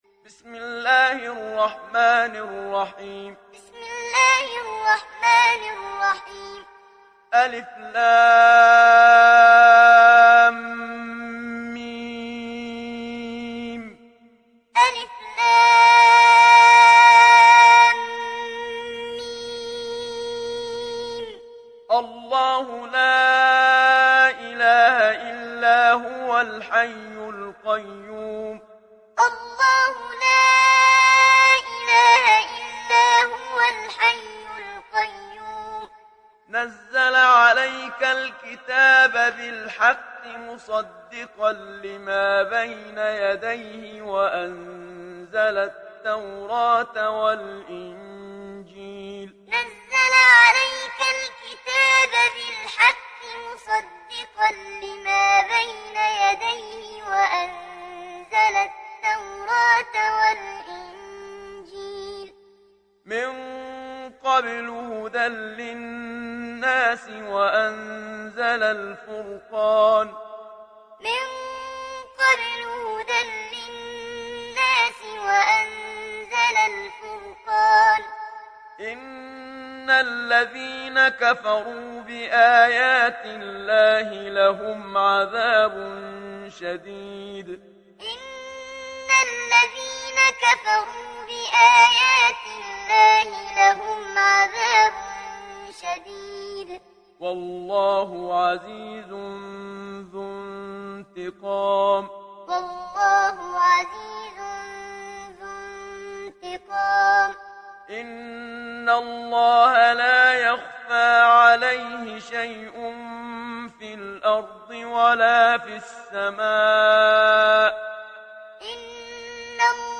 تحميل سورة آل عمران mp3 بصوت محمد صديق المنشاوي معلم برواية حفص عن عاصم, تحميل استماع القرآن الكريم على الجوال mp3 كاملا بروابط مباشرة وسريعة
تحميل سورة آل عمران محمد صديق المنشاوي معلم